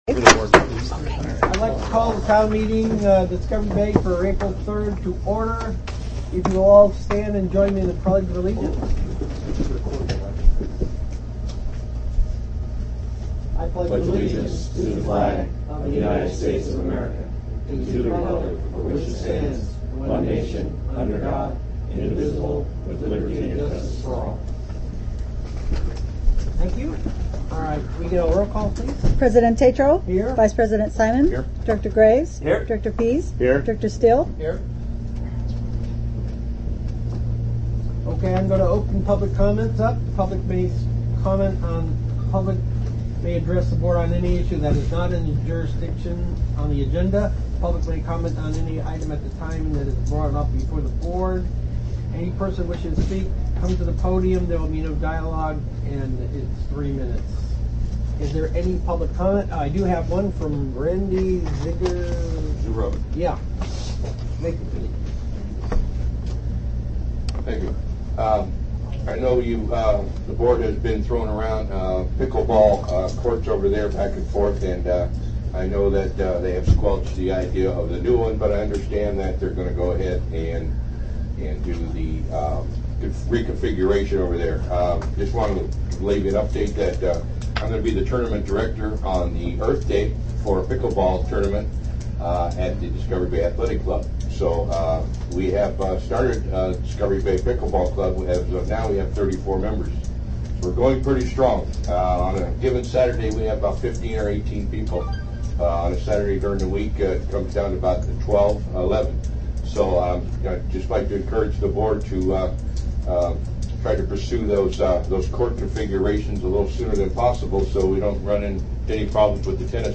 Board of Directors Special Meeting